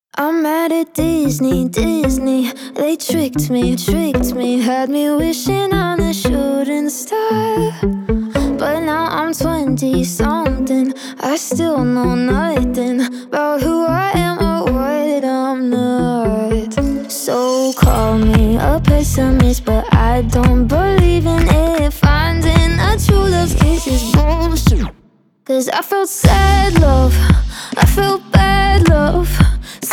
• Pop
The song's instrumentation is "synth-heavy".